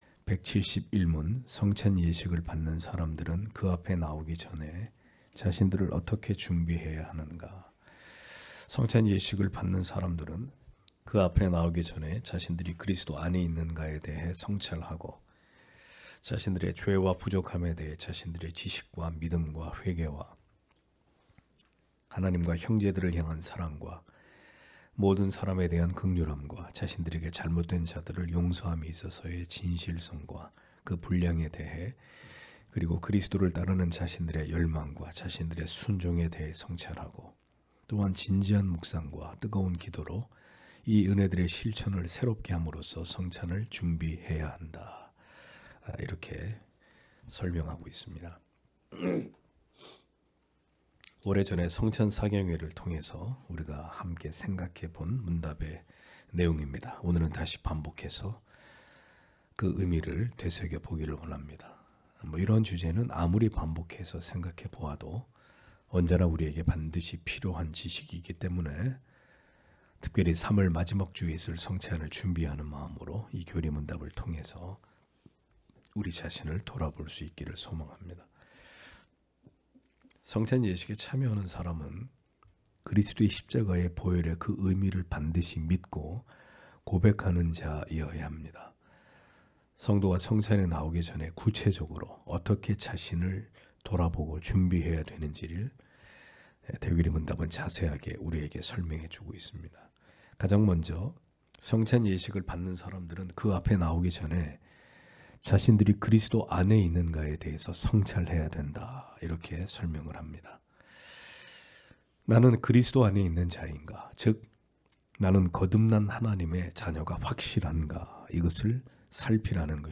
주일오후예배 - [웨스트민스터 대요리문답 해설 171] 171문) 성찬의 성례를 받고자 하는 사람은 성찬에 참여하기 전에 어떠한 준비를 해야 하는가? (마가복음 8장27-33절)
* 설교 파일을 다운 받으시려면 아래 설교 제목을 클릭해서 다운 받으시면 됩니다.